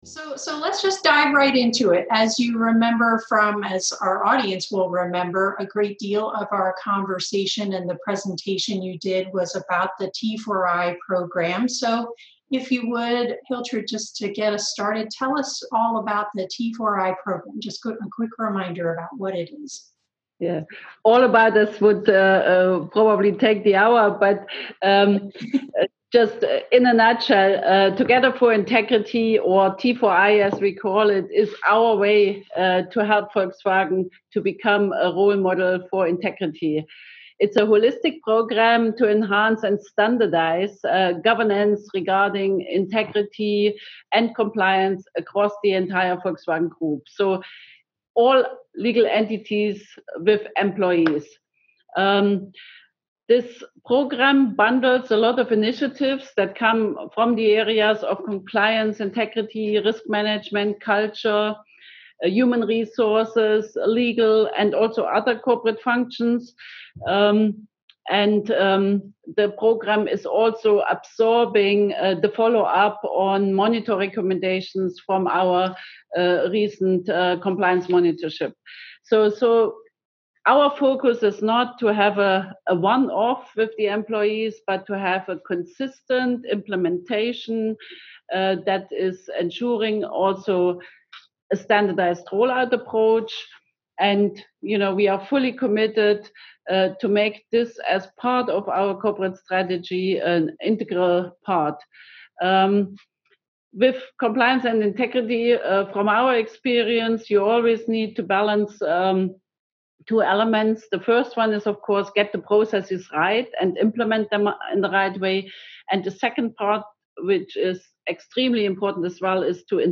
How did Volkswagen AG use the Together4Integrity program to bounce back from their worst-case scenario? A Q&A with VW integrity leaders.